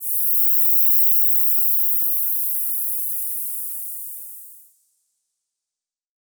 Index of /musicradar/shimmer-and-sparkle-samples/Filtered Noise Hits
SaS_NoiseFilterB-06.wav